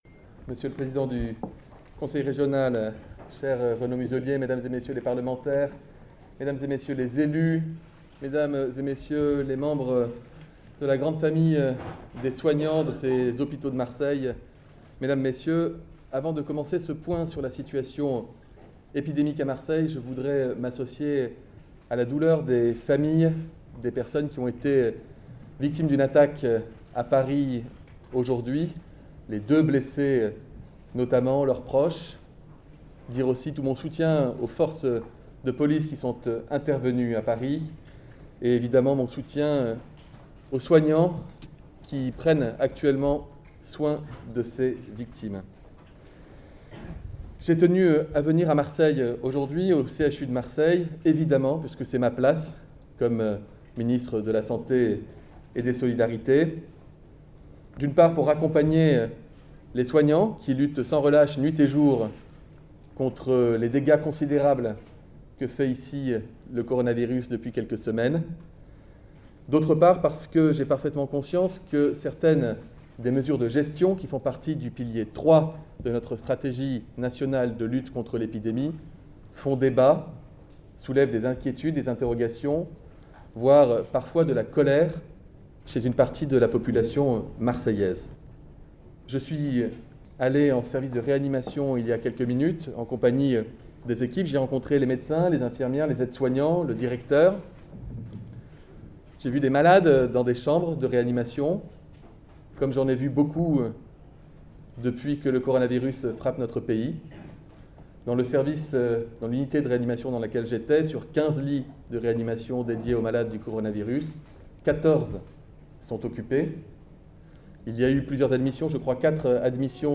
son_copie_petit-438.jpg«On peut concerter, on ne peut pas se permettre de tergiverser», a lancé Olivier Véran, ministre des solidarités et de la santé à la Timone, CHU de Marseille devant un parterre de représentants du monde médical et d’élus avant d’affirmer: «Mon seul objectif est de protéger les Français».
olivier_veran_discours_total_25_09_20.mp3